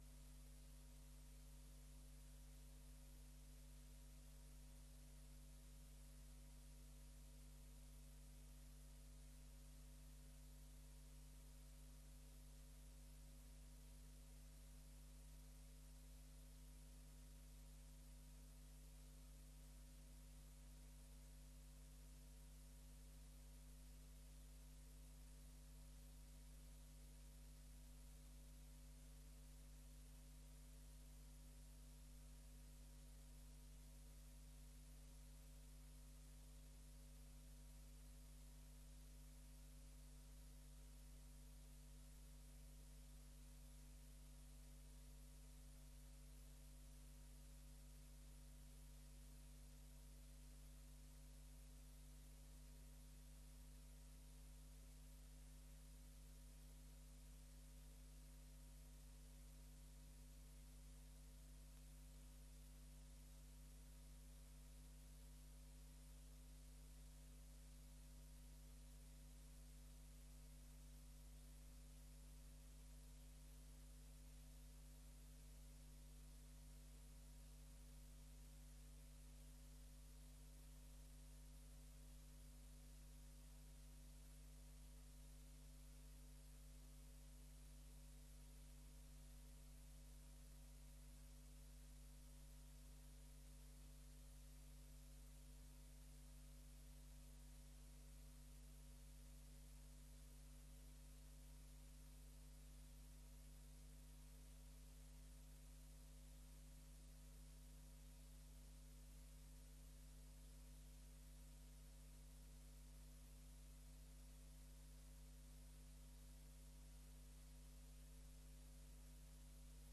Download de volledige audio van deze vergadering